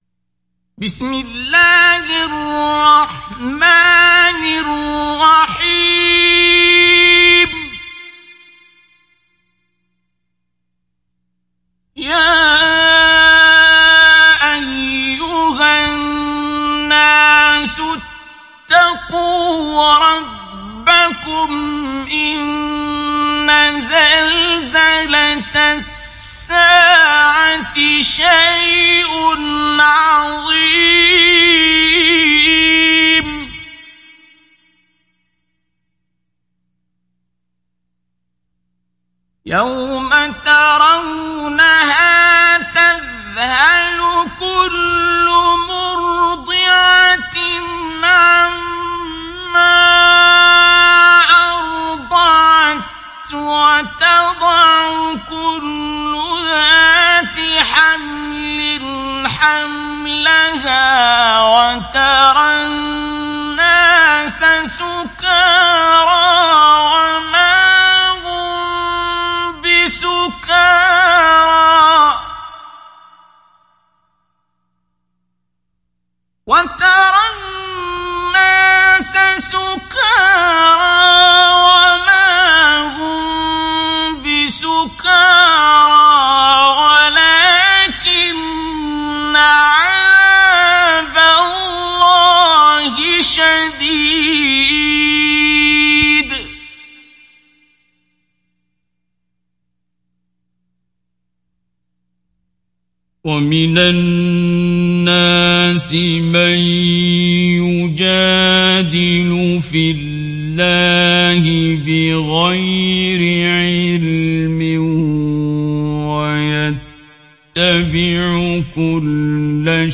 ডাউনলোড সূরা আল-হাজ্জ Abdul Basit Abd Alsamad Mujawwad